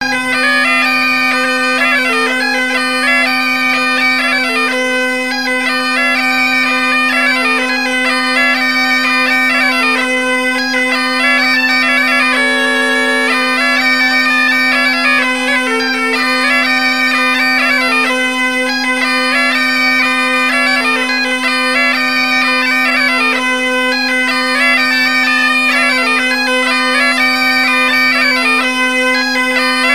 gestuel : à marcher
Pièce musicale éditée